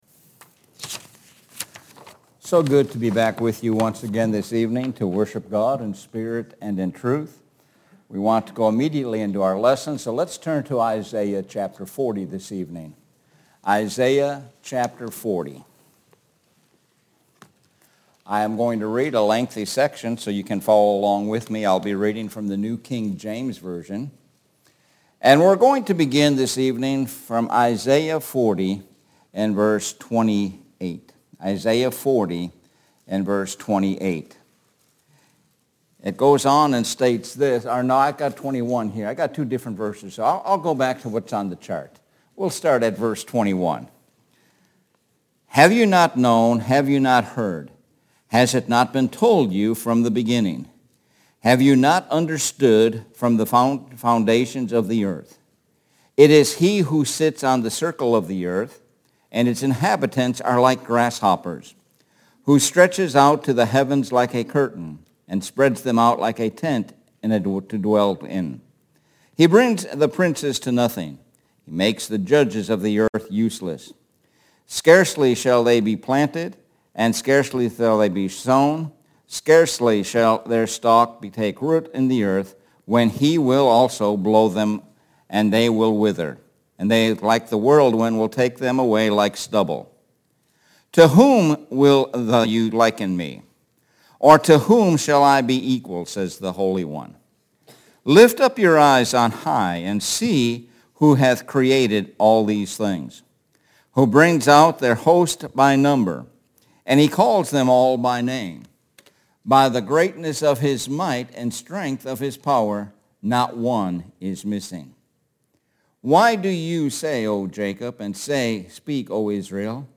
Lakeland Hills Blvd Church of Christ